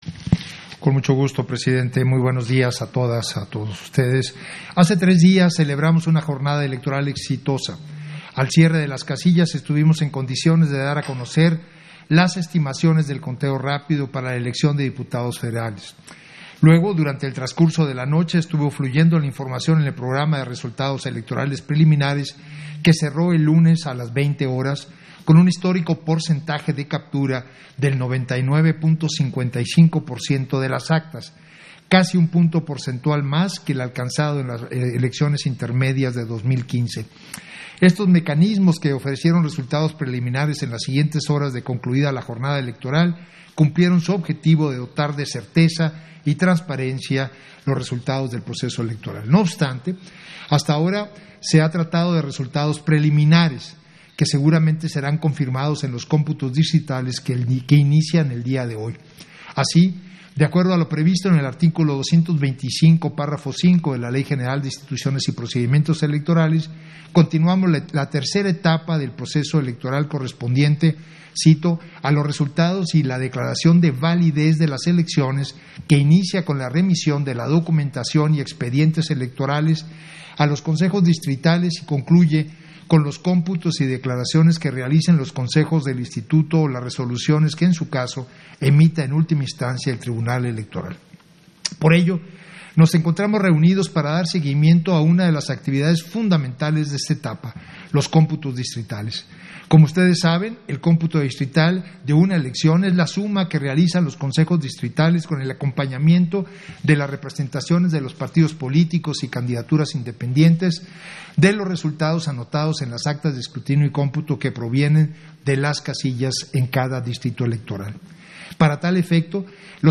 Intervención de Edmundo Jacobo Molina, en el punto 3 de la Sesión Extraordinaria, relativo al informe sobre la instalación de los Consejo Locales y Distritales para la realización de Cómputos Distritales